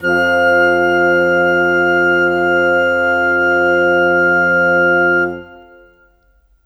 Rock-Pop 22 Bassoon _ Flute 02.wav